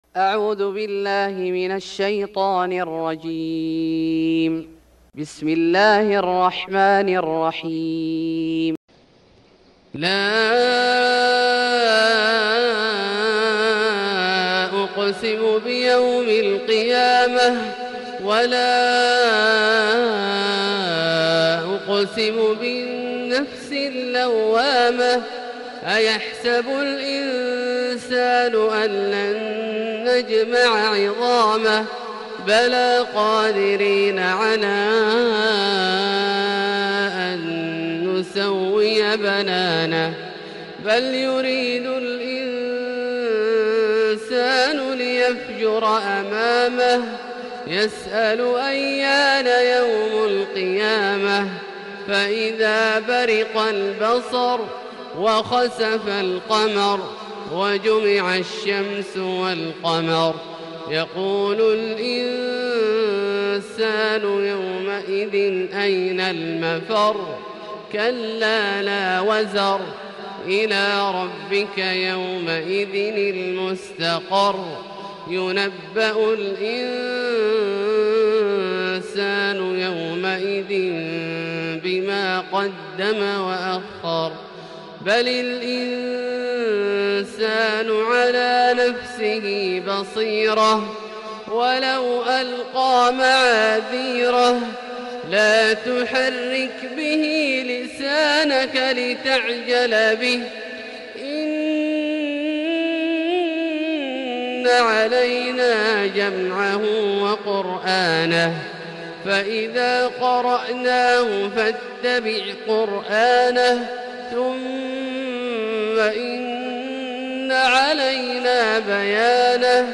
سورة القيامة Surat Al-Qiyamh > مصحف الشيخ عبدالله الجهني من الحرم المكي > المصحف - تلاوات الحرمين